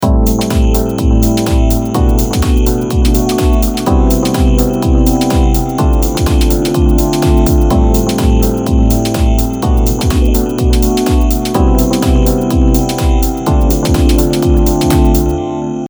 では、並列でかけてみましょう。
ブロック1と2が横並びになった分、現れてくる効果も同じくらいになりました。ひとつ前のサウンドと比べるとブロック1の音がより鮮明に聴こえますね。